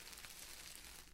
sonido pasos
描述：free sound, efects sonido de pasos en pasillo
标签： steps wood free efects sound
声道立体声